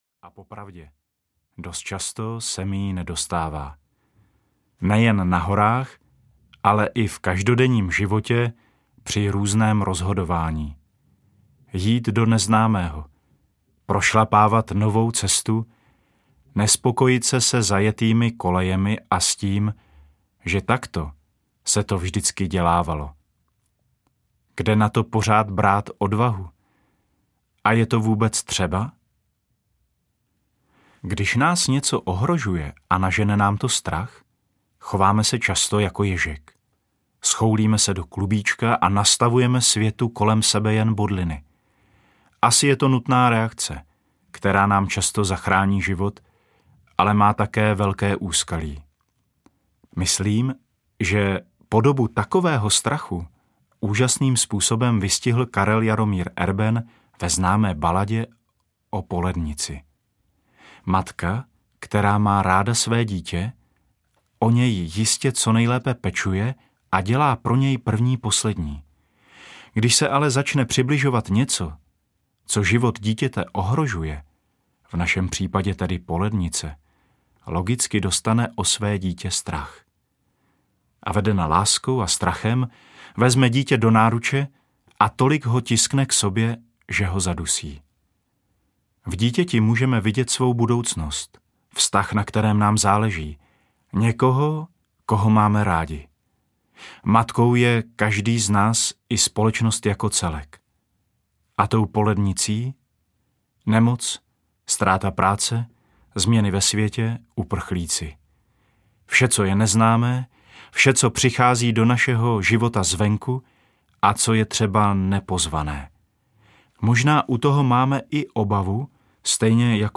Pán se stará audiokniha
Ukázka z knihy
pan-se-stara-audiokniha